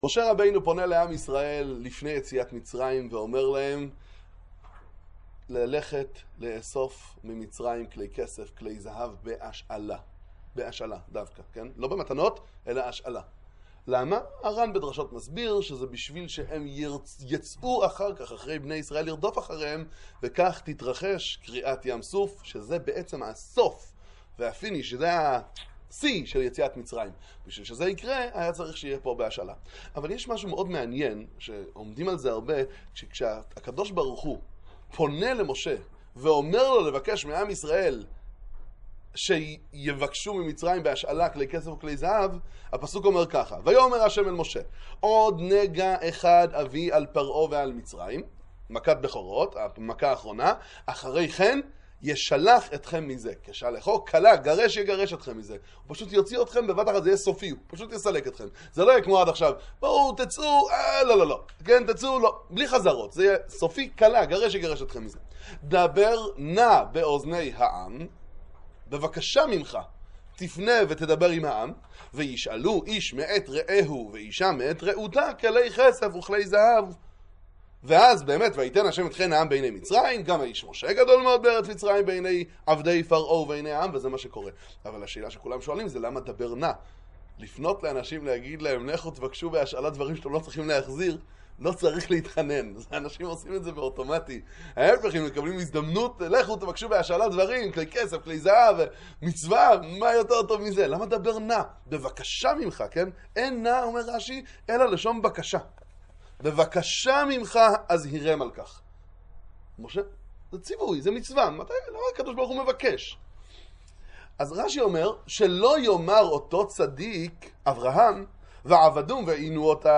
אני יהודי טוב! דבר תורה קצר לפרשת בא